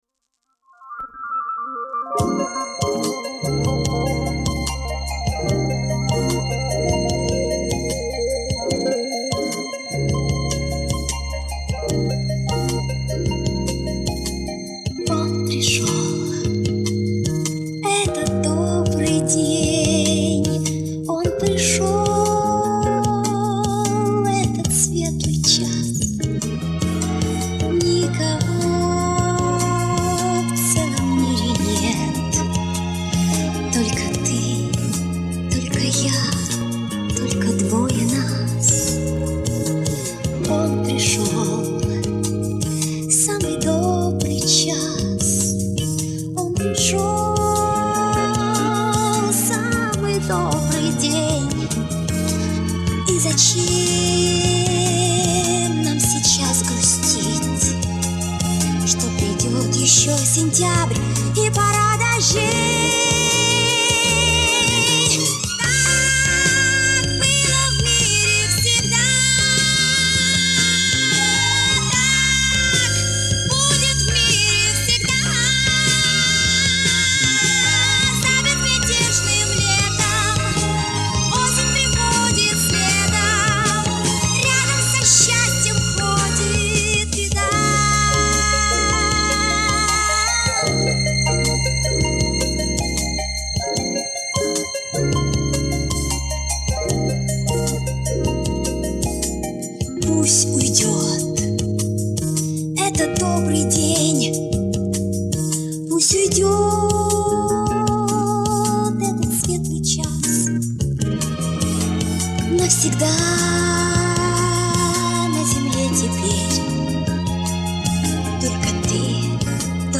Решили сделать дуэт, дописать стихи.
Мой голос звучал чуть выше.